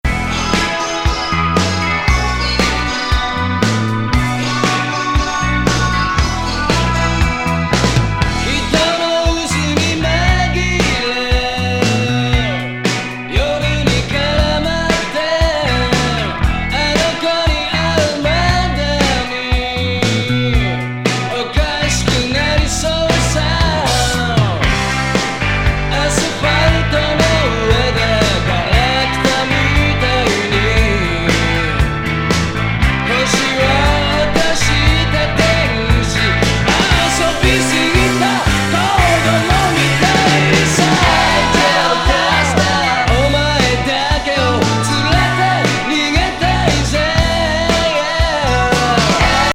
ファンキー・ロック!